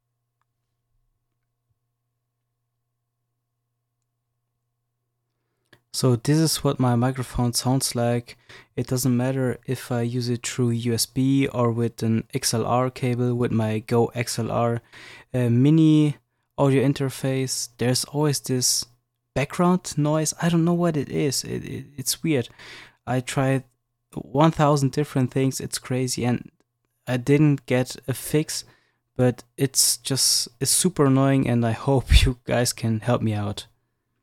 Shure Mv7 weird background noise
Hi guys, I just recently bought a new Shure Mv7 mic and unfortunately I have a very weird background noise that I can not get rid of, for me it sounds almost like the fans from my pc but they are really not loud so I assume it's some kind of electric static noise that the fans are maybe providing?